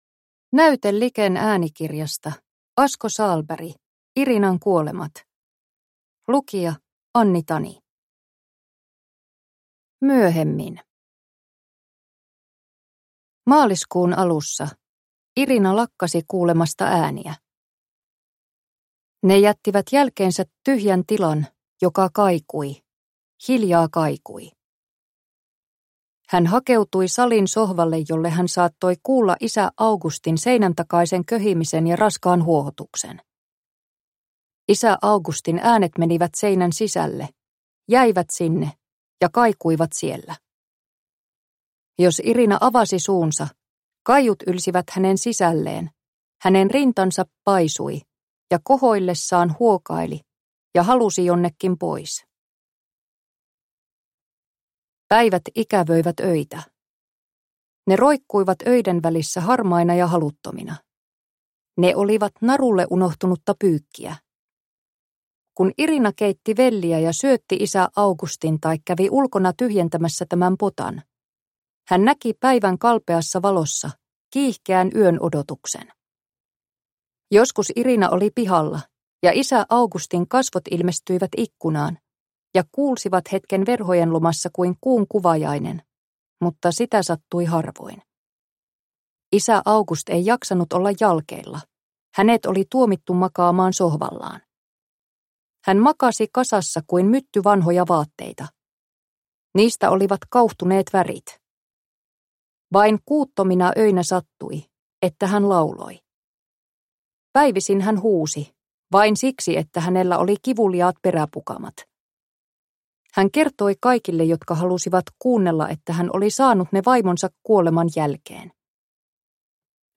Irinan kuolemat – Ljudbok – Laddas ner